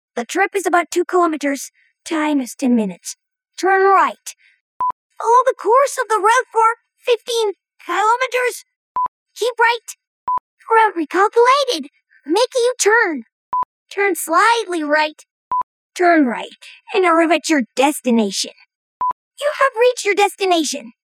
Нашёл TTS с голосами поней.